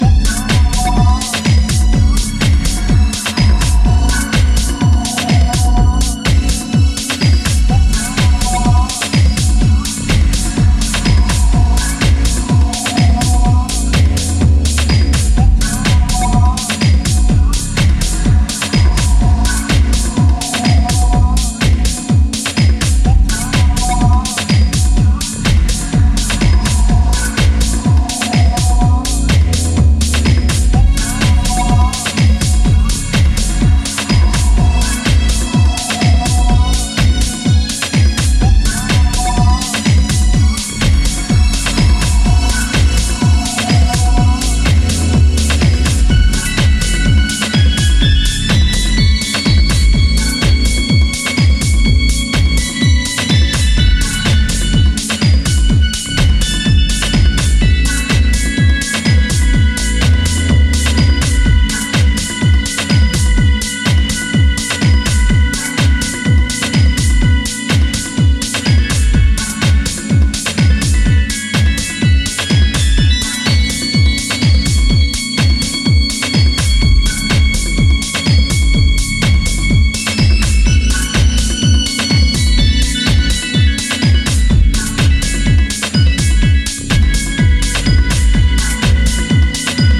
ミニマルでソリッドにシェイプされたタイムレスな魅力溢れるディープ・ハウスの逸品がずらり揃った、素晴らしい好内容盤です！
ジャンル(スタイル) DEEP HOUSE